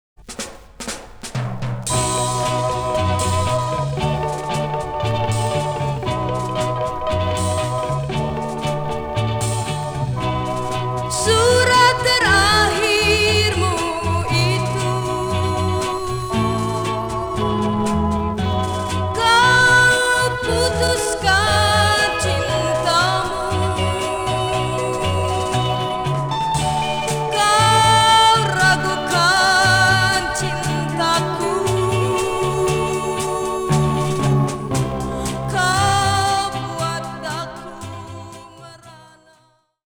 The goal is to retain all of the original sound quality of the record but remove the noise associated with vinyl (clicks and pops) especially from older hard to find releases.